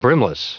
Prononciation du mot brimless en anglais (fichier audio)
Prononciation du mot : brimless